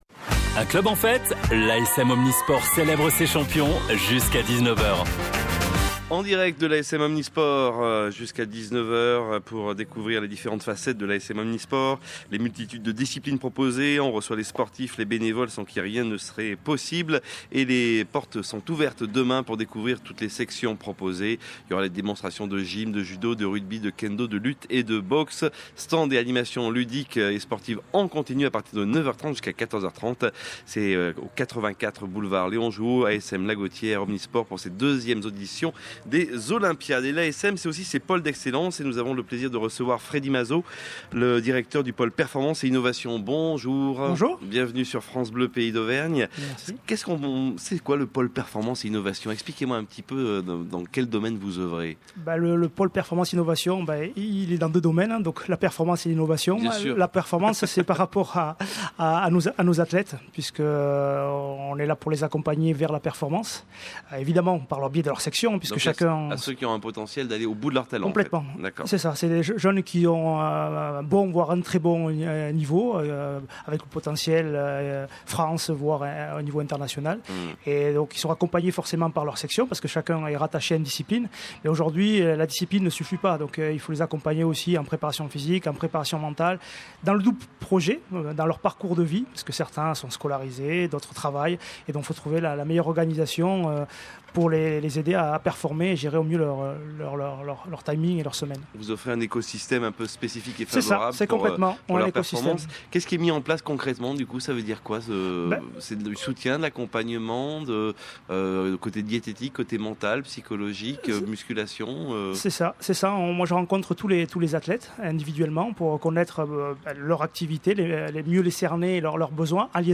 Le vendredi 23 juin dernier, dans le cadre de nos Olympiades ASM, France Bleu Pays d’Auvergne s’est installée à la Gauthière pour diffuser une émission spéciale ASM en direct, de 16h à 19h.
Jusqu’au 28 août, retrouvez les 14 interviews extraites de cette émission spéciale.